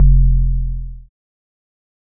808 (Decent).wav